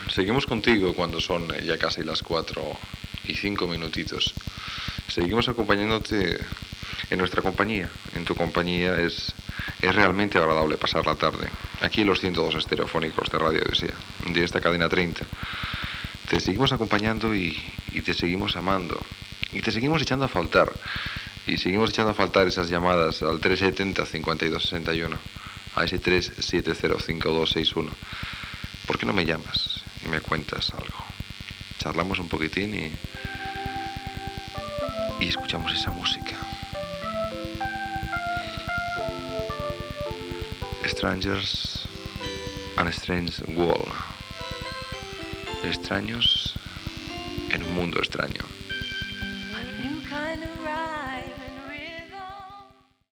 e5f9ba9928bf664b4d093c43ba855134e80f653a.mp3 Títol Radio Odisea Emissora Radio Odisea Cadena Cadena 30 Radio Titularitat Tercer sector Tercer sector Musical Descripció Identificació de l'emissora, telèfon i tema musical.